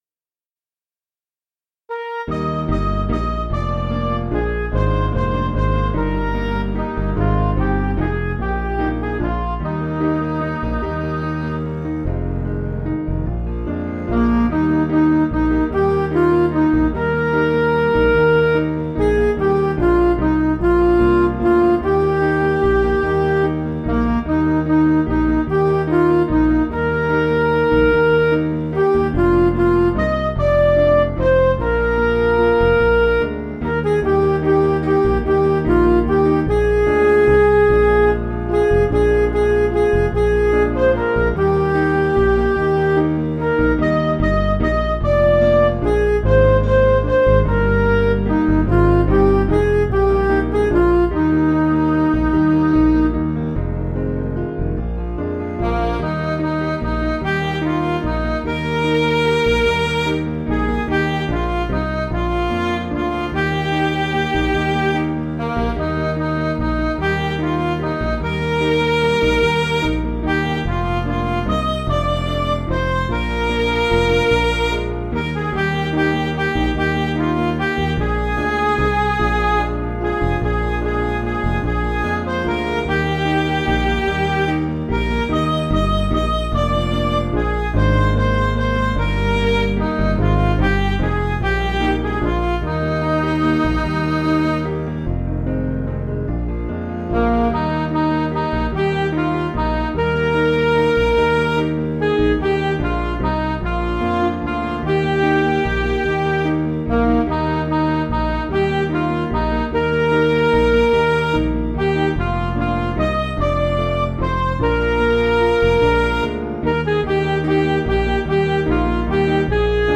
Piano & Instrumental
(CM)   4/Eb
Midi